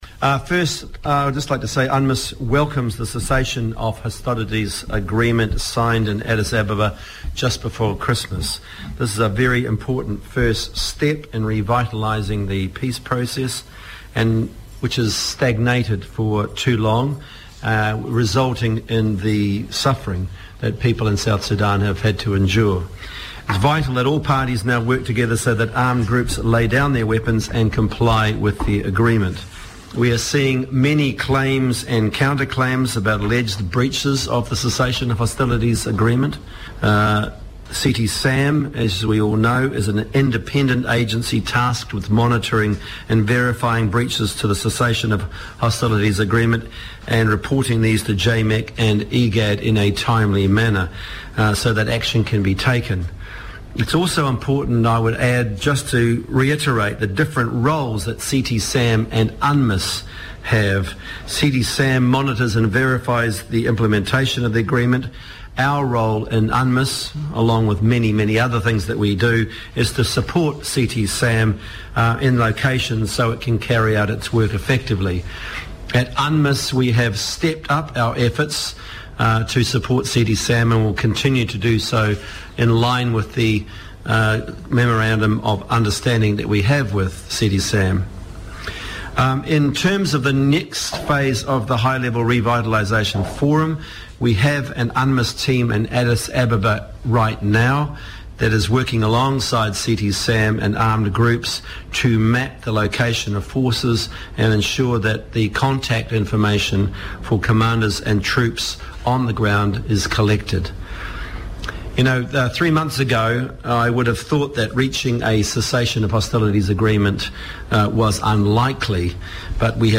UN Special Representative David Shearer press statement 24.01.2018
Speaking at a media briefing in Juba on Wednesday, Mr Shearer said the High-Level Revitalization Forum set to resume in Addis Ababa, Ethiopia on the 5th of February, is an important step in revitalizing the peace process - and said any party in violation of the agreement should be held to account.